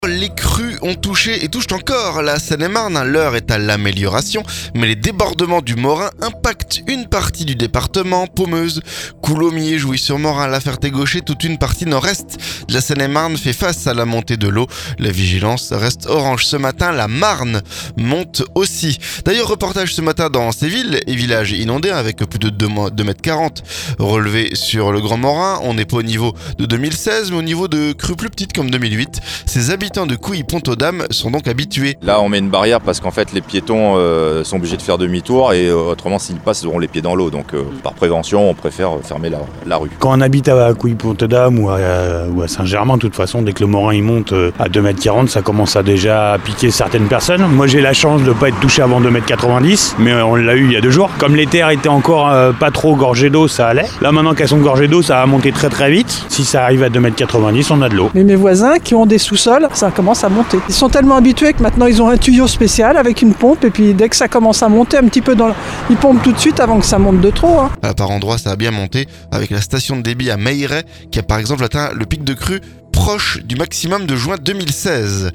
INONDATIONS - Reportage à Couilly-pont-aux-dames, où le Grand morin déborde
Reportage ce mercredi dans ces villes et villages inondés. Plus de 2m40 relevés sur le Grand Morin dans certaines communes, soit le niveau des crues de 2008 mais pas encore du tout au niveau de 2016. Ces habitants de Couilly-pont-aux-dames sont habitués.